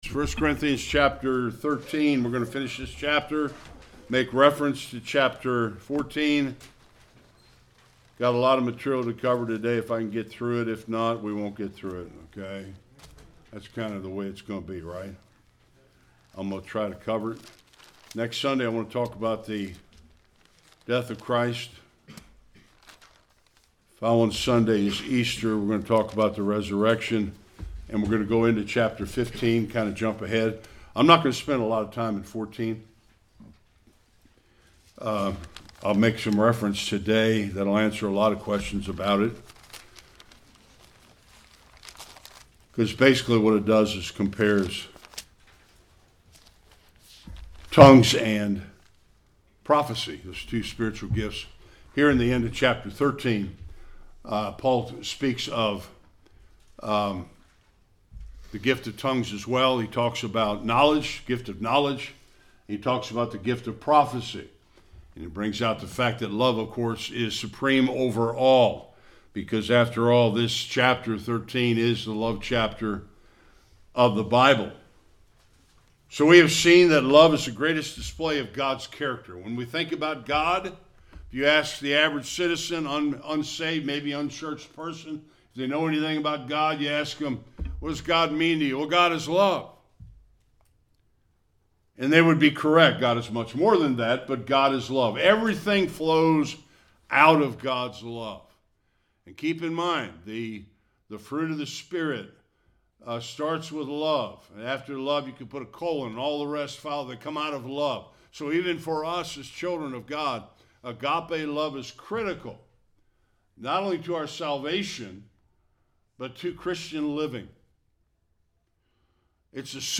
8 Service Type: Sunday Worship Are any spiritual gifts temporary?